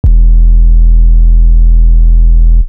Sizzle 808.wav